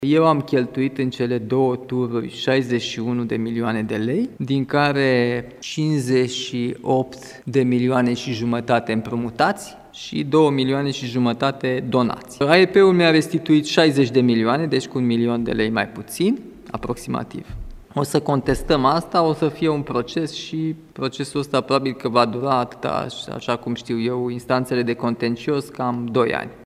Președintele României, Nicușor Dan: „AEP mi-a restituit 60 de milioane, deci cu un milion de lei mai puțin, aproximativ”